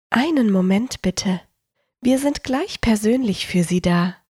Professionelle Sprecherin
Warteschleife